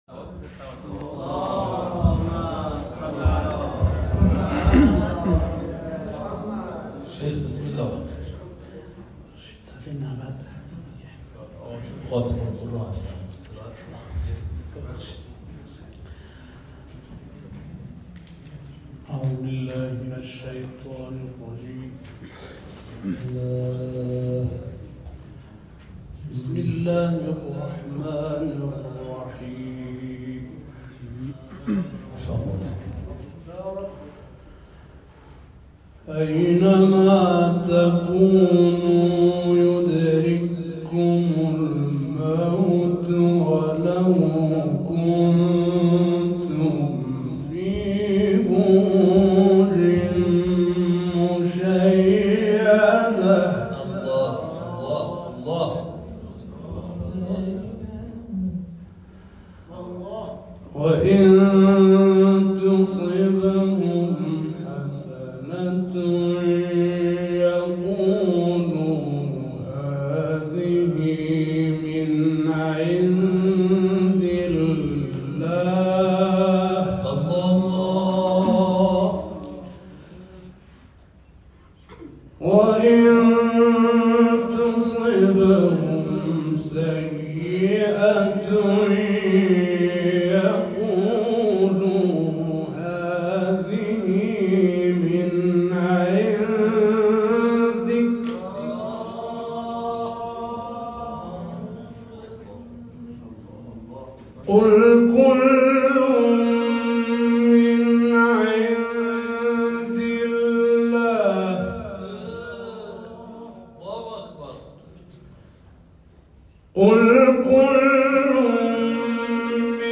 تلاوت‌هایی از چند نسل + صوت